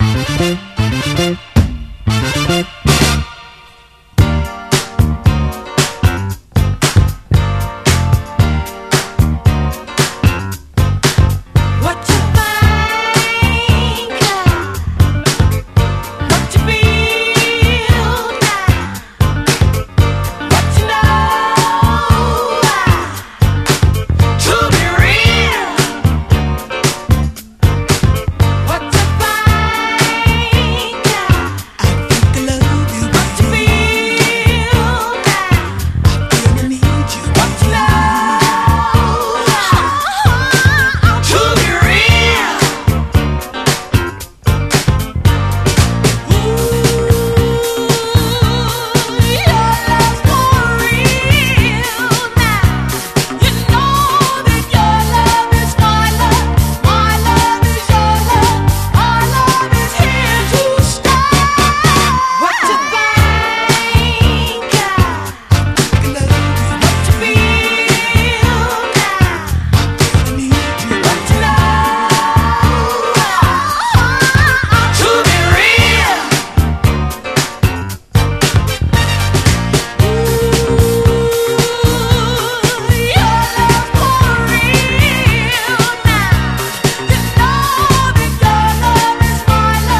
SOUL / SOUL / 70'S～ / NORTHERN SOUL / FUNKY SOUL